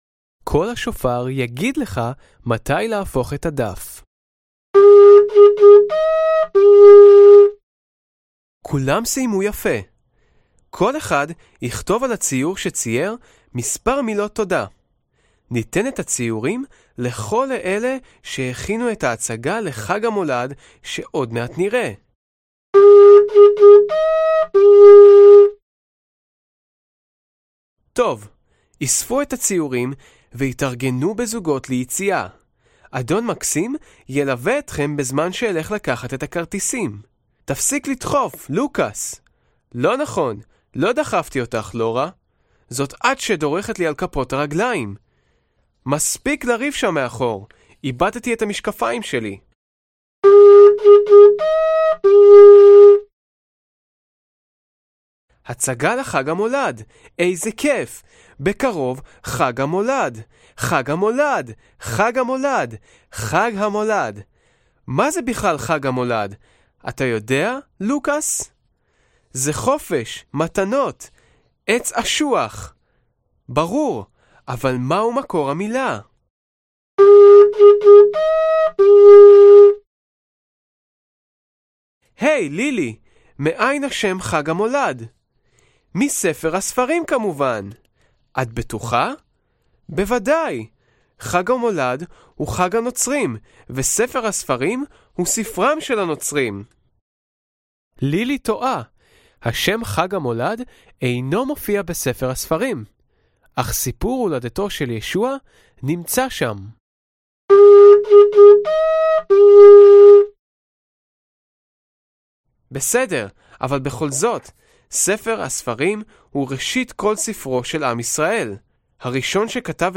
Avi 1 Livre Audio hebrew